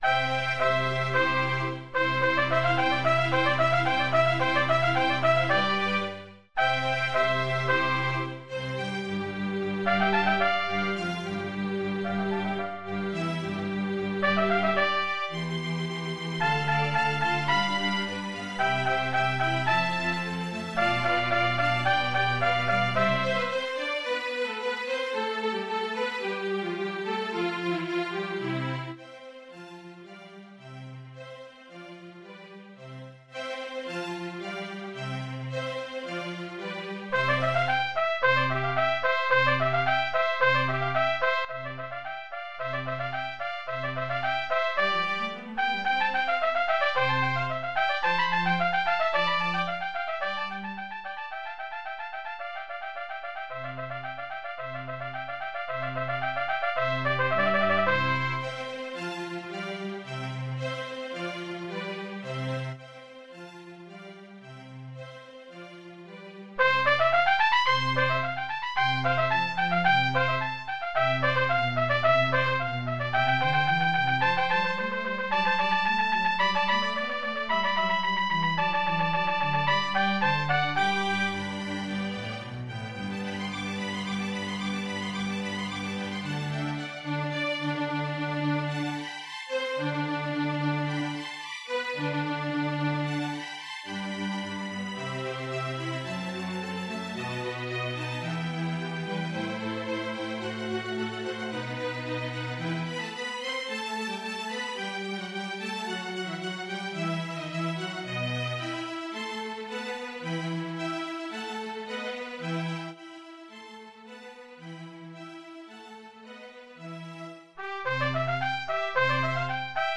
Voicing: Trumpet w/orch